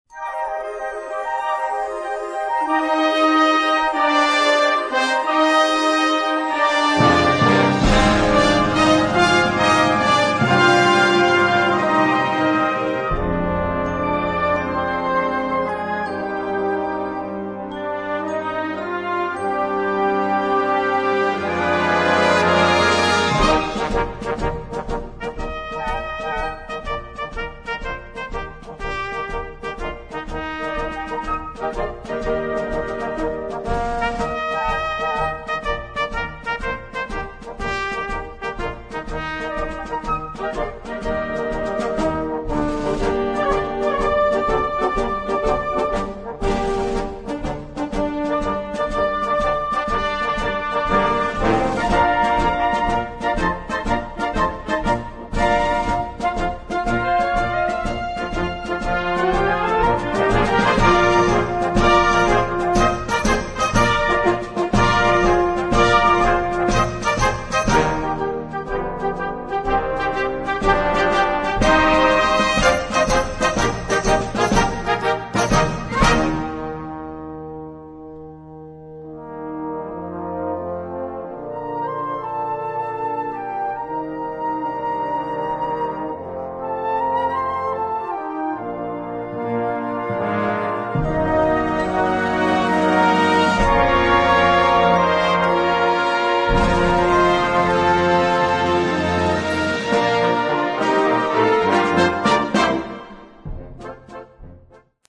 colorfully orchestrated medley
Partitions pour orchestre d'harmonie.